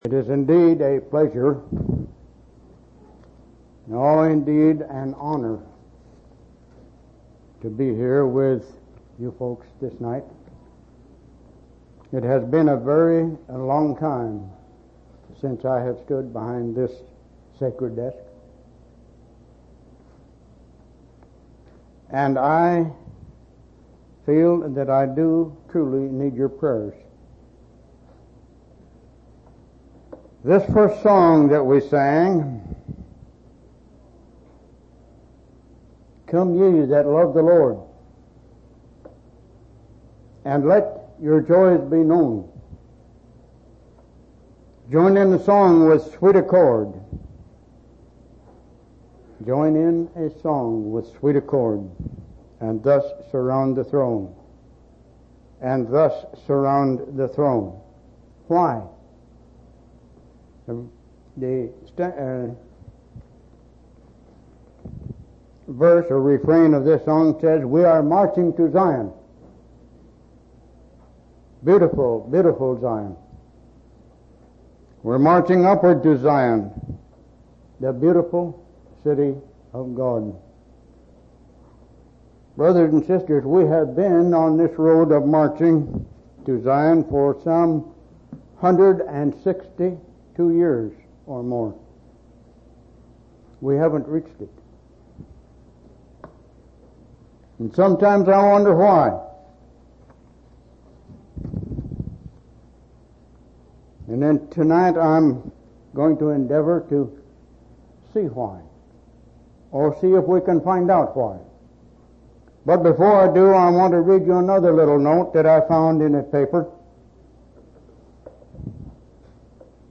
7/19/1992 Location: Temple Lot Local Event